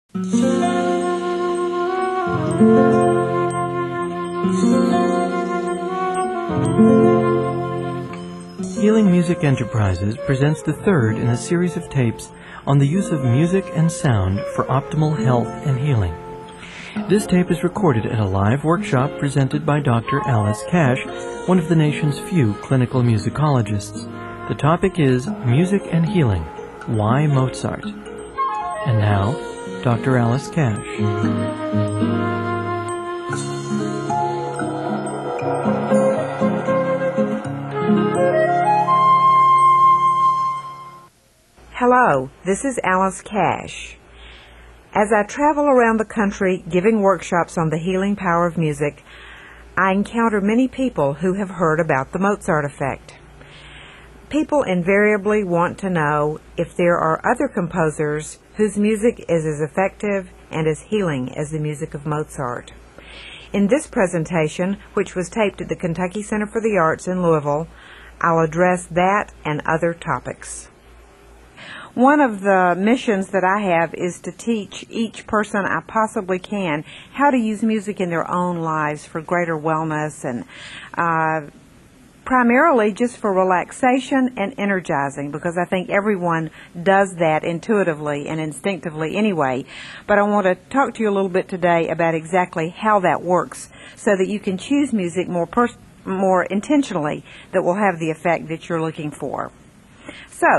Enjoy hearing a qualified clinical musicologist discuss the pros and cons of using Mozart and other great composers for healing effects.